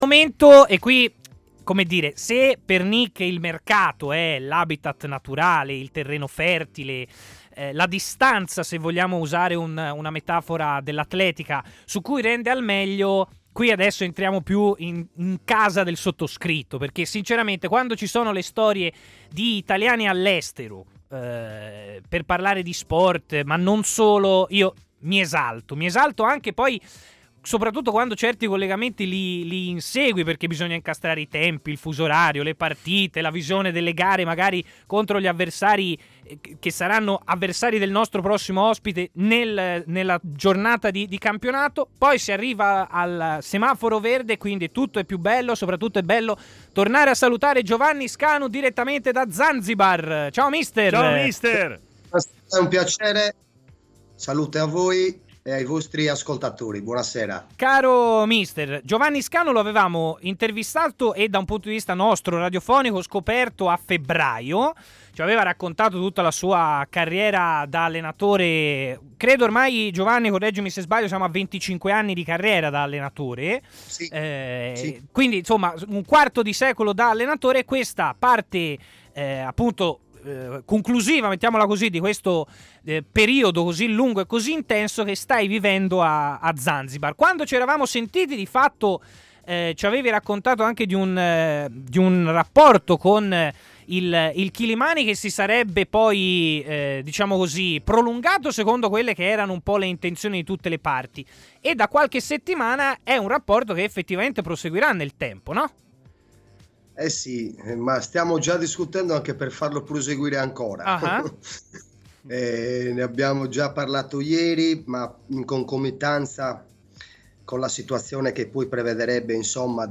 intervenuto a Stadio Aperto, trasmissione pomeridiana di TMW Radio